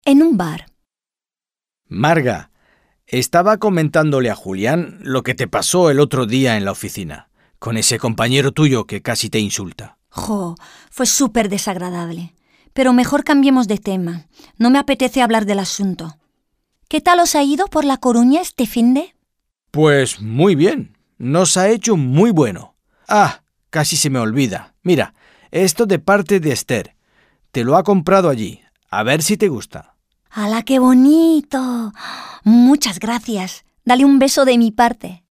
Dialogue - En un bar